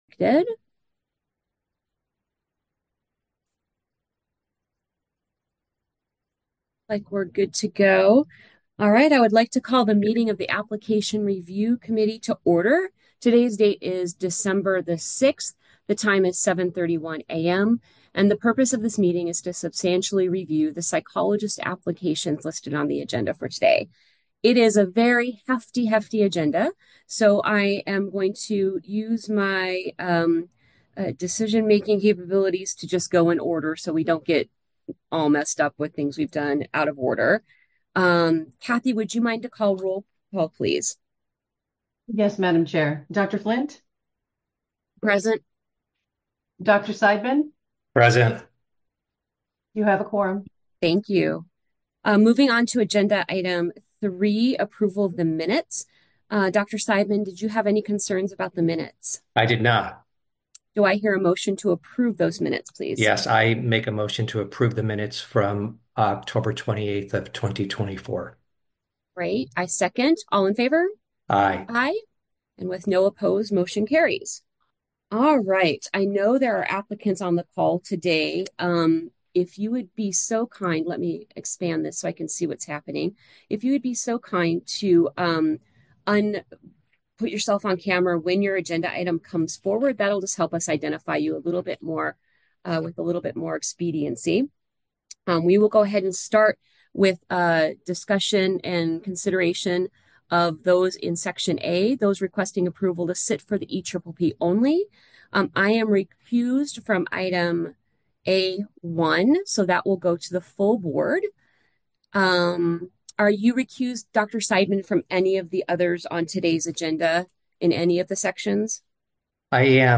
Application Review Committee Meeting | Board of Psychologist Examiners
Members will participate via Zoom.